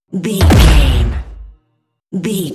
Dramatic drum hit deep
Sound Effects
Atonal
heavy
intense
dark
aggressive